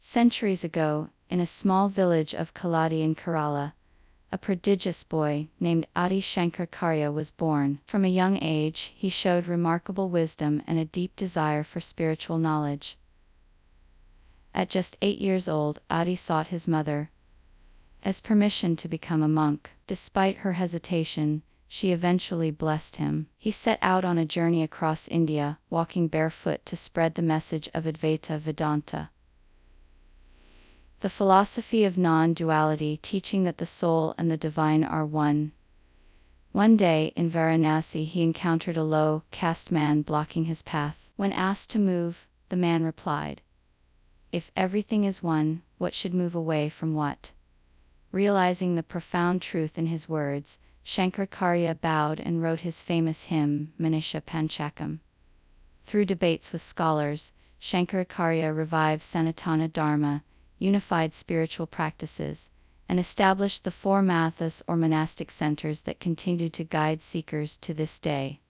5. Listening to the Final AI-Generated Audio
Click the play button to hear the synthesized speech: